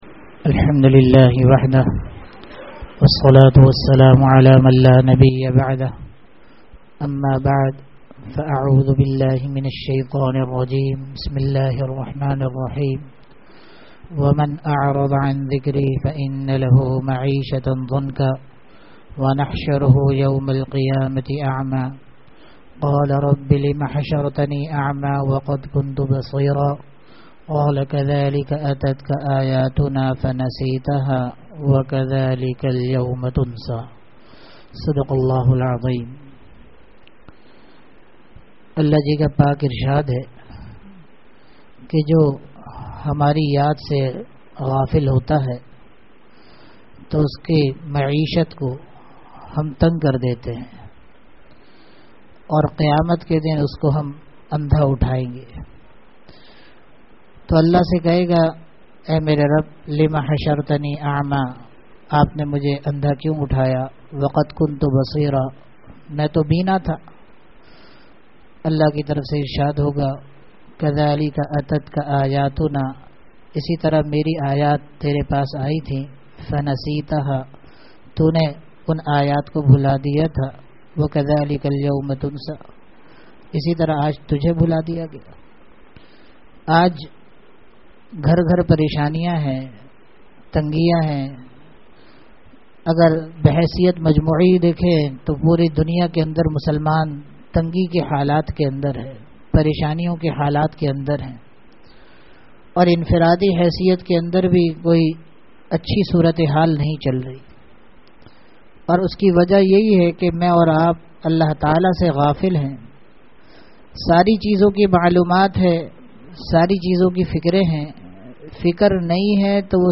Bayanat
Bayanat (Jumma Aur Itwar) Toba ki mazboti ke lie allah walon ki suhbat zarori he (jummah byan )